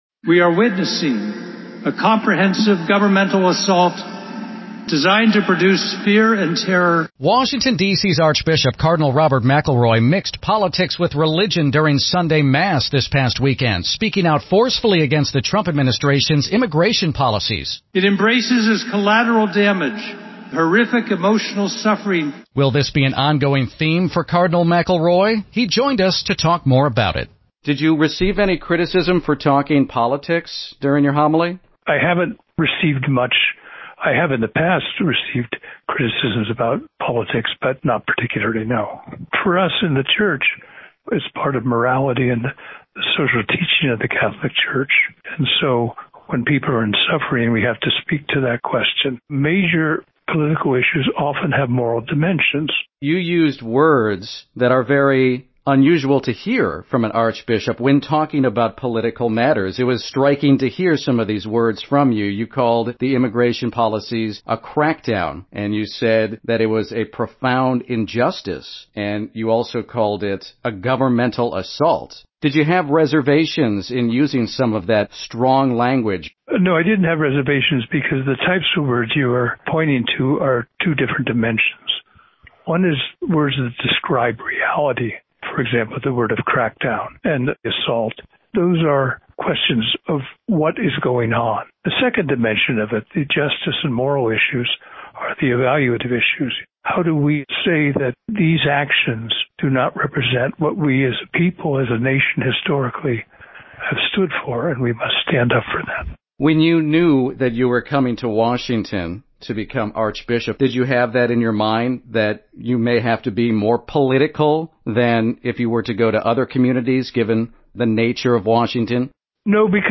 ARCHBISHOP-INTERVIEW_otter_ai.mp3